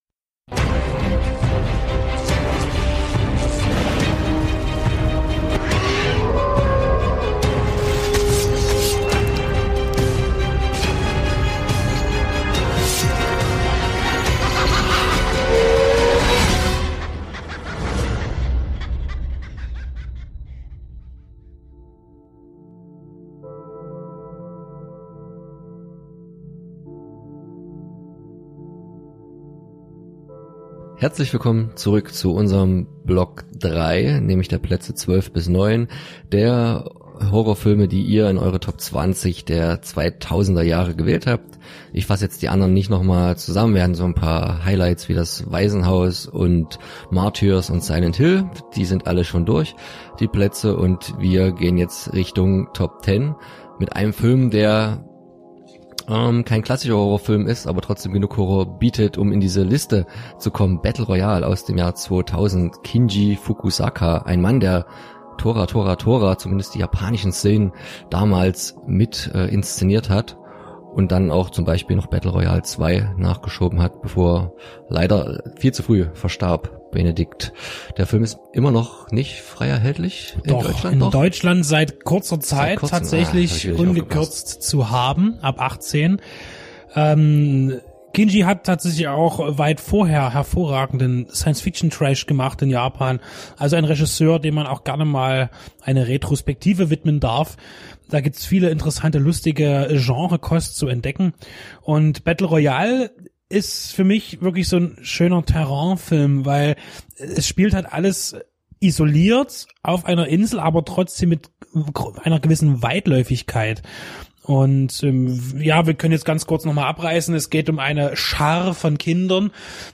Herzlich Willkommen zur Mitte der 2000er-Horror-Talk-Reihe.
Euer Podcast-Quartett der besonderen Art spricht über die Plätze Zwölf bis Neun. Dabei werden wir über Kinji Fukasakus Battle Royal reden und klären warum dieser besser ist, als alle Hunger-Games dieser Welt.